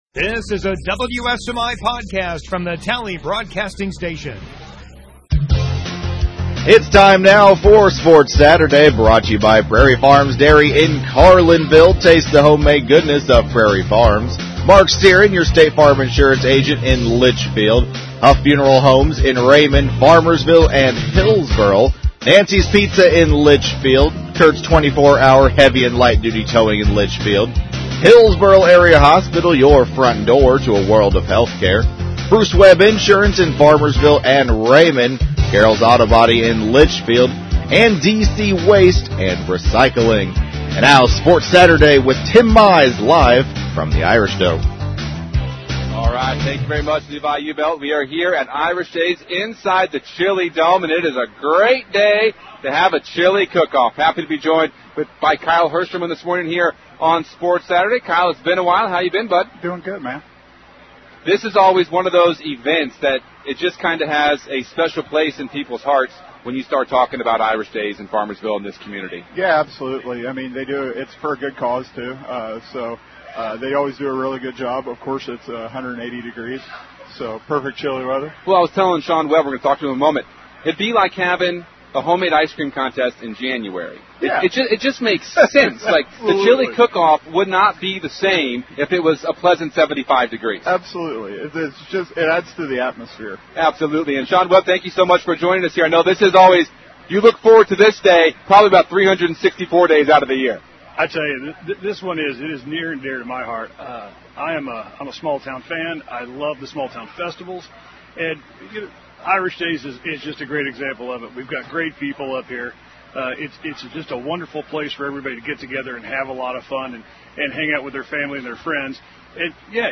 from the Chili Dome at Irish Days in Farmersville